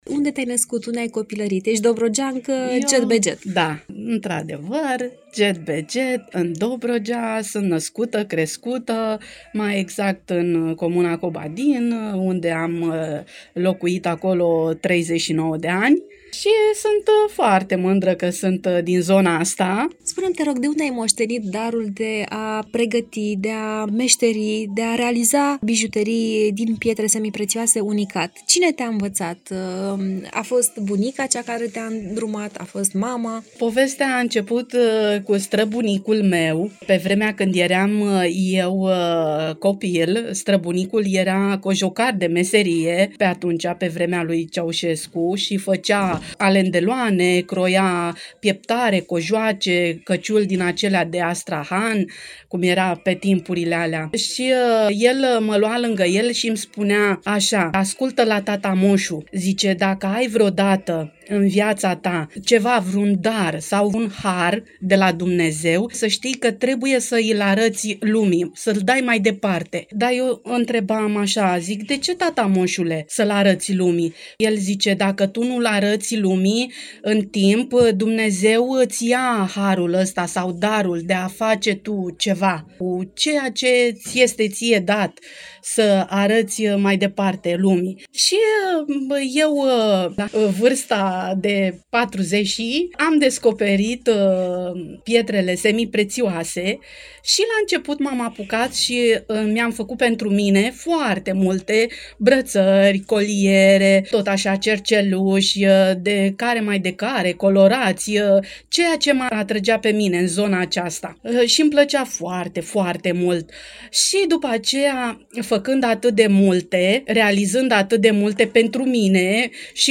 INTERVIU-ARTIST-PIETRE.mp3